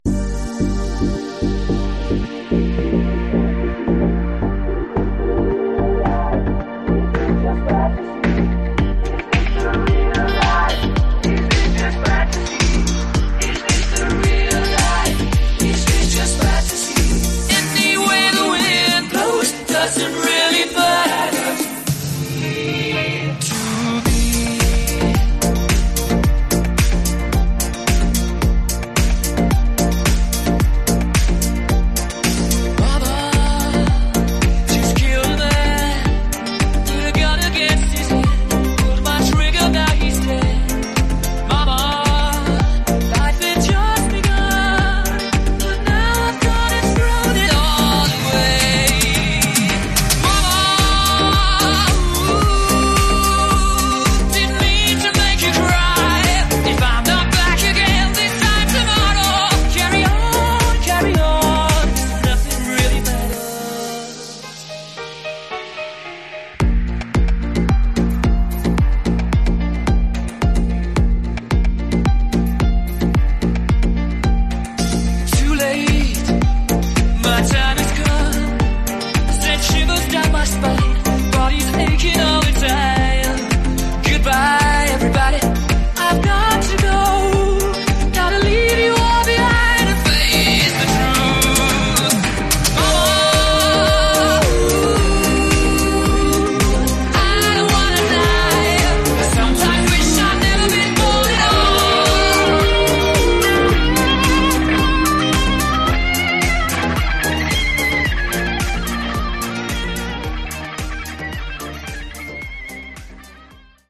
format: 5" megamix CD